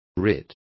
Complete with pronunciation of the translation of writs.